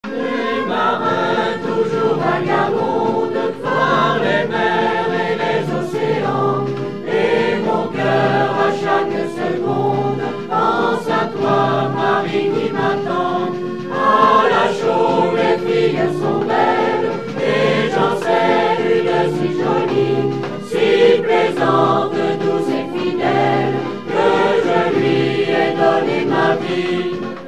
Veillées Chaumoises choeur
Pièce musicale inédite